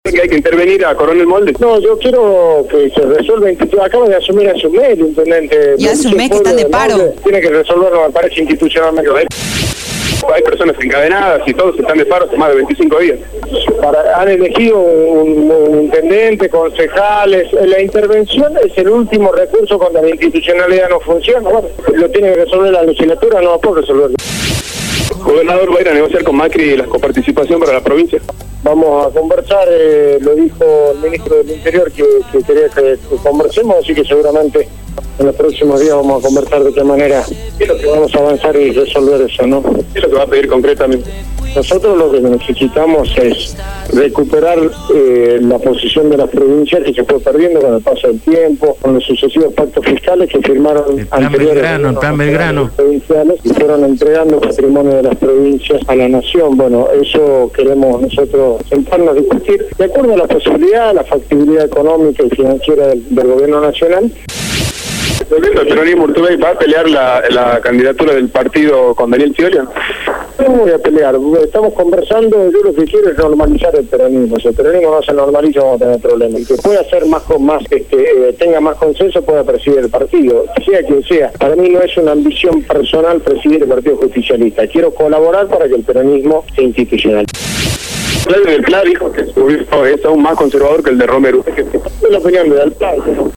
URTUBEY-RONDA-DE-PRENSA.mp3